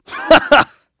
Clause_ice-Vox_Happy2.wav